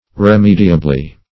Meaning of remediably. remediably synonyms, pronunciation, spelling and more from Free Dictionary.
remediably.mp3